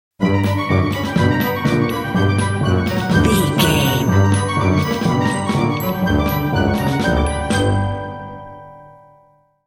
A fast/zany orchestral piece with hints of comical mischief.
Mixolydian
Fast
energetic
lively
playful
flute
oboe
strings
orchestra
harp